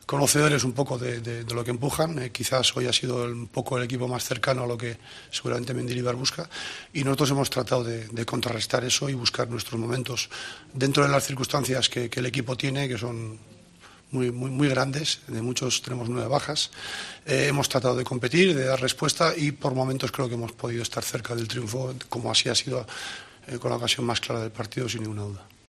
El entrenador del Sevilla valoró el empate en Vitoria: "Dentro de las circunstancias que el equipo tiene, que son muy grandes, tenemos nueve bajas, hemos tratado de competir".